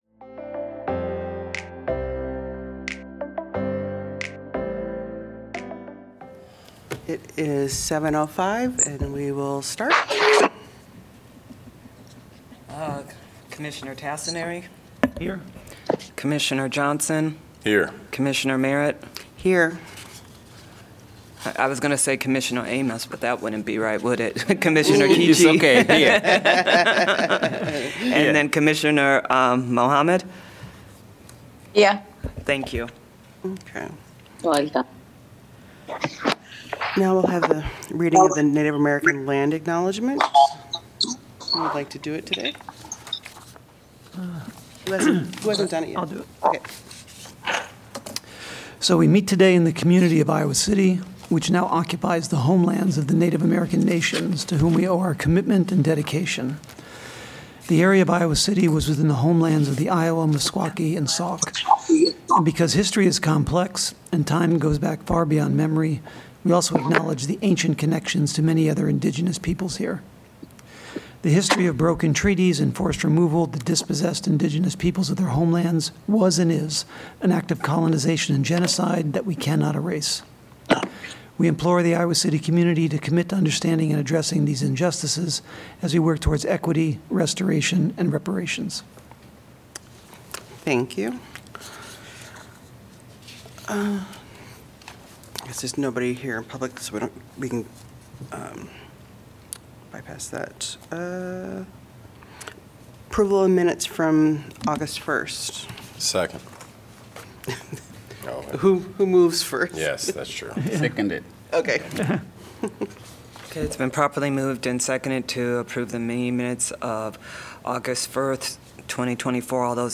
Regular semi-monthly meeting of the Ad Hoc Truth and Reconciliation Commission.